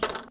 MenuOn.wav